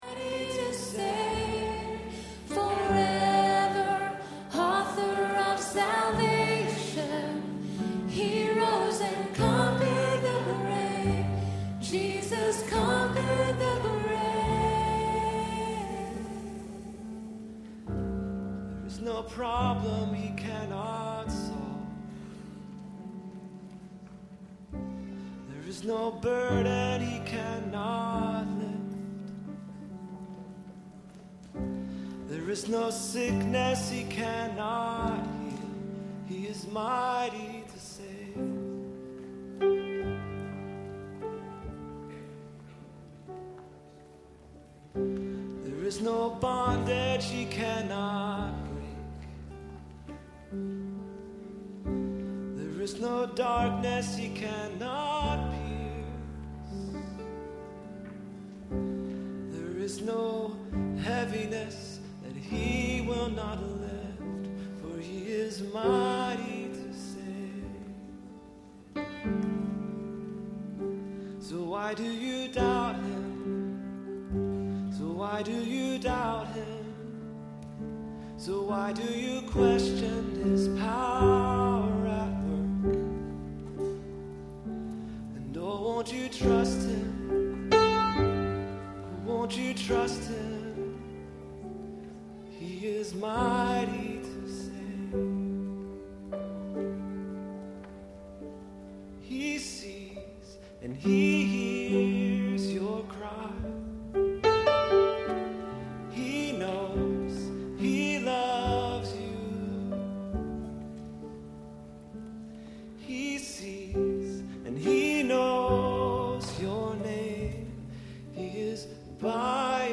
Several Sunday mornings ago I sounded like a frog.
We finished “Mighty to Save”, and then, froggy voice and all, I sang a spontaneous song before we went back into the chorus.
And here’s how it sounded. Not pretty, but real.
mighty-to-save-spontaneous-7-17-11.mp3